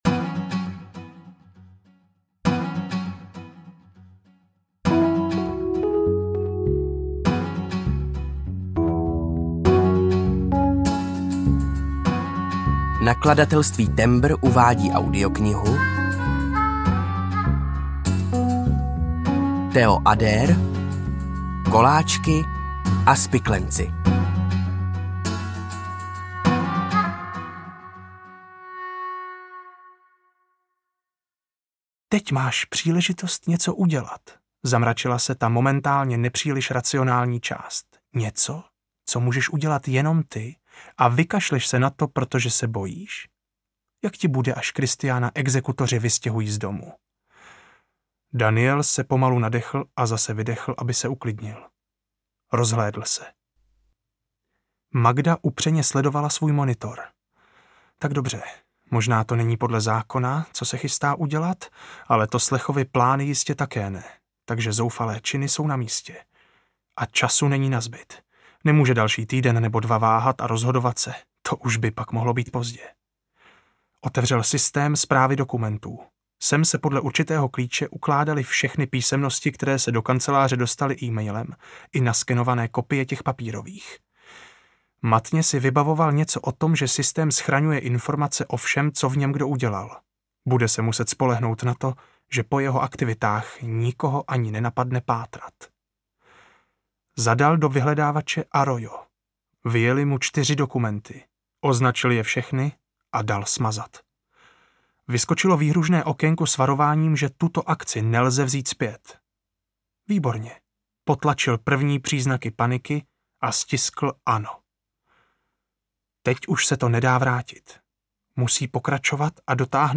Koláčky a spiklenci audiokniha
Ukázka z knihy